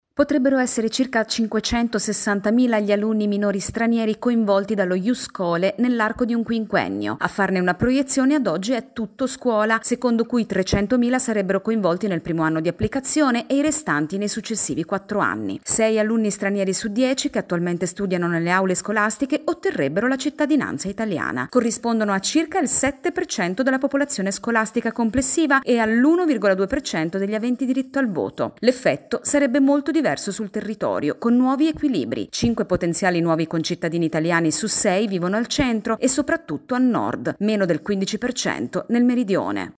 Da oggi a domenica oltre 4mila sportivi scendono in campo per le finali nazionali dei campionati AiCS che si intrecciano con i World Sports Games. Il commento